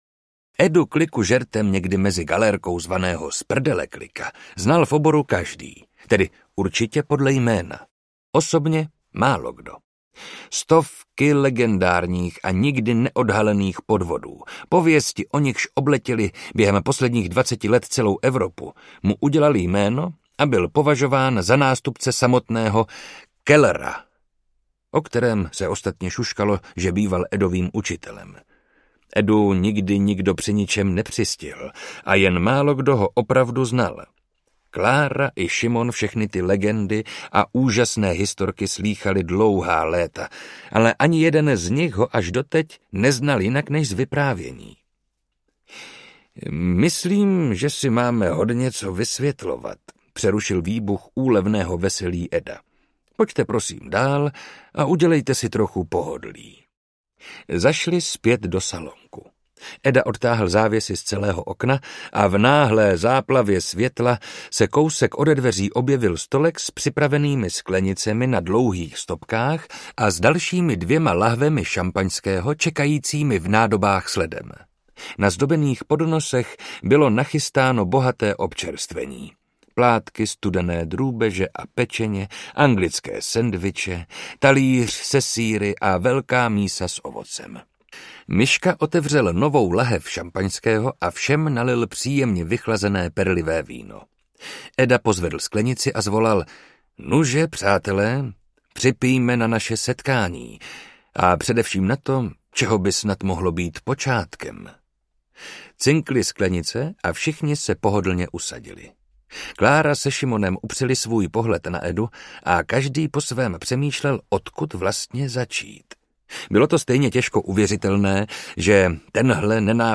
Komtur audiokniha
Ukázka z knihy
Čte Marek Holý.
Vyrobilo studio Soundguru.